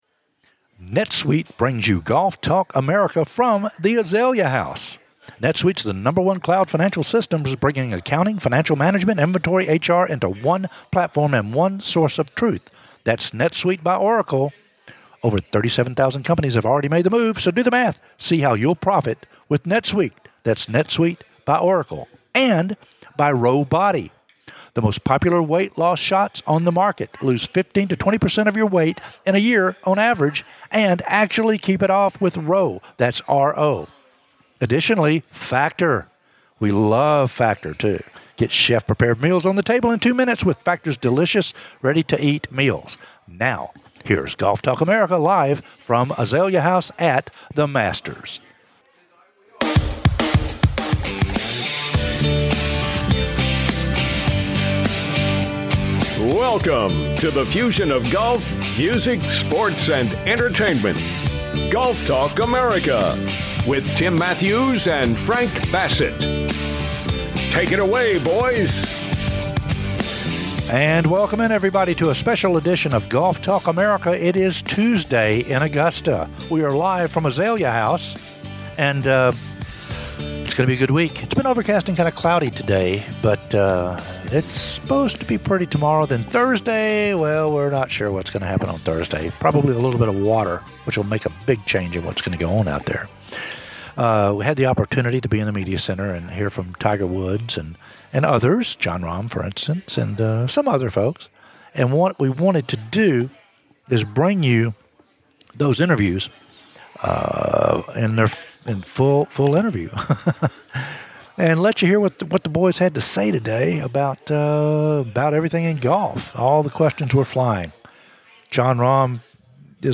Interviews from "THE MASTERS" with Tiger, John Rahm & Akshay Bhatia...